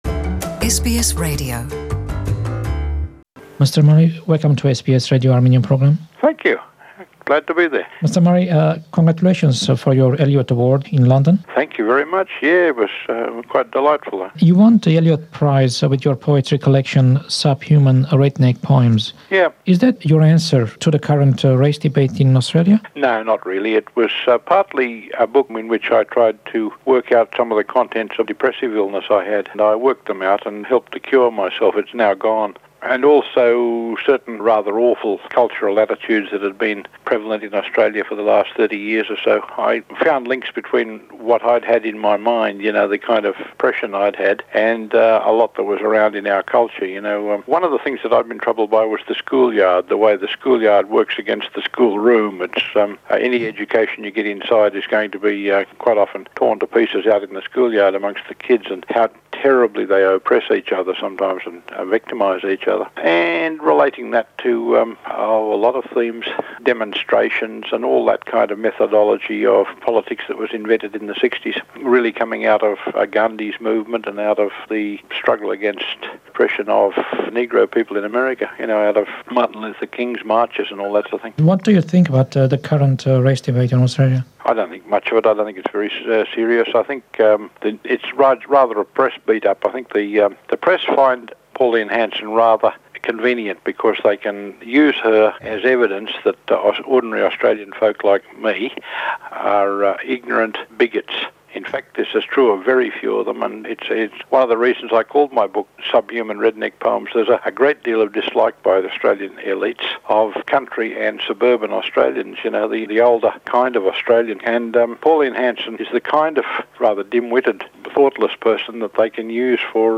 An interview from our archives. In January 1997, Les Murray in an interview with SBS Armenian, discussed race issues in Australia and his upcoming novel (Fredy Neptune published in 1998) and why he included the Armenian Genocide in his novel.